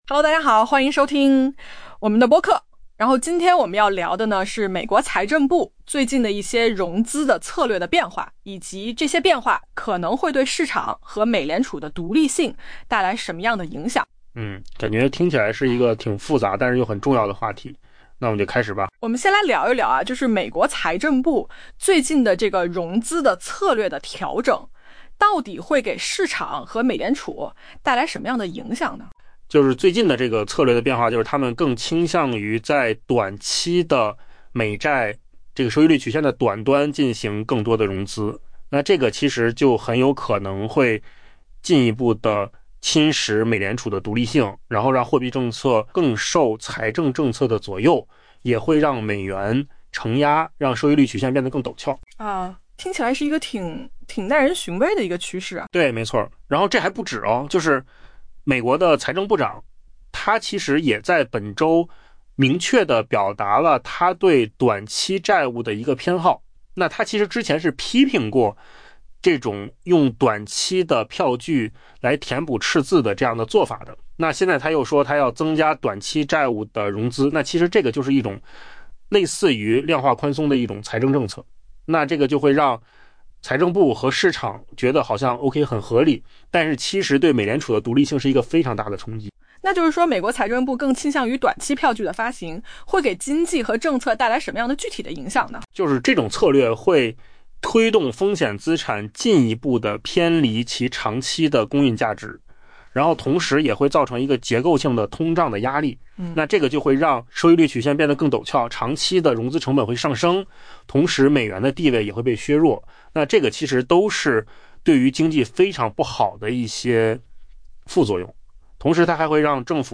AI播客：换个方式听新闻下载mp3
音频由扣子空间生成